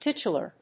音節tit・u・lar 発音記号・読み方
/títʃʊlɚ(米国英語), títʃʊlə(英国英語)/